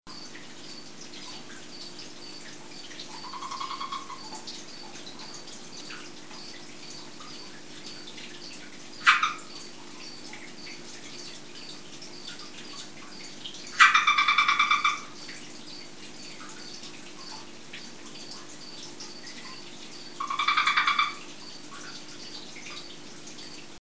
Haltung Fotos, Filme, Audio Links Rotaugen Startseite Agalychnis callidryas - Medien Rotaugenlaubfrosch - Album Kleiner Film: Hyla callidryas sucht sich Schlafplatz (7,7mb) Ruf von Agalychnis callidryas Paarungsrufe